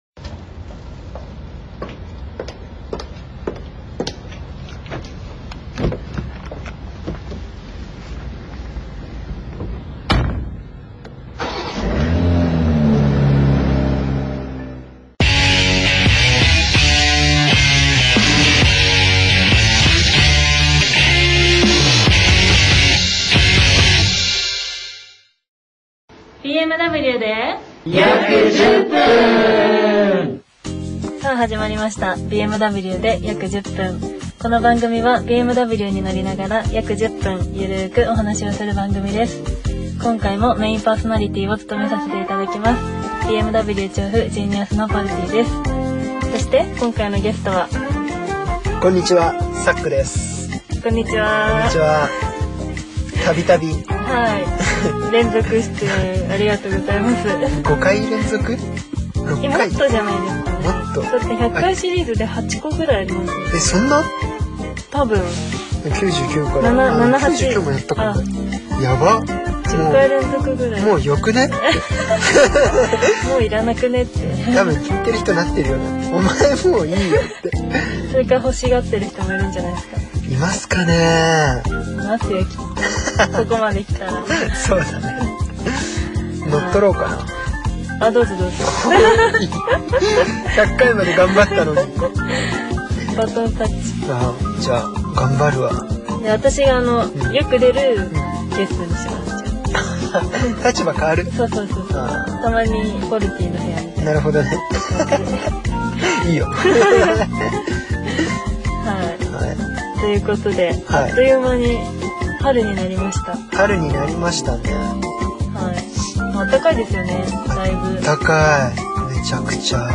いよいよ春になりトークも緩さ全開です！